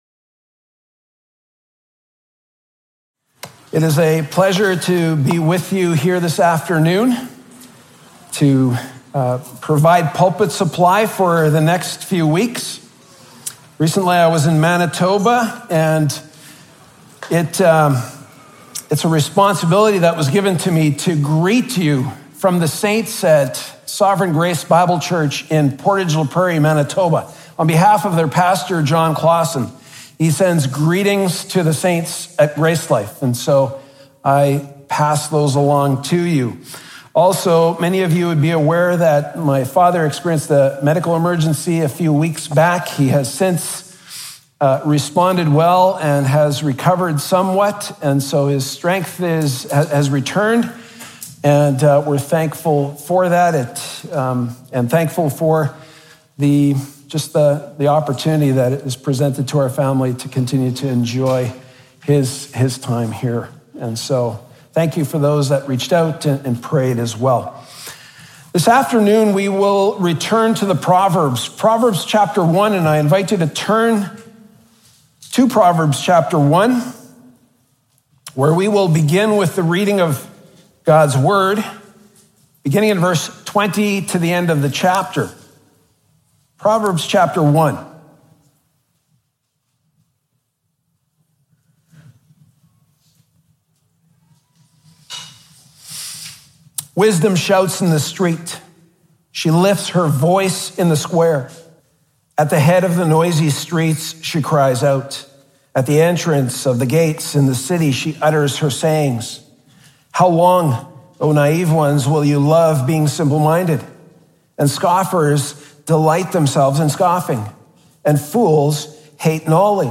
Category: Pulpit Sermons Key Passage: Prov 1:8-19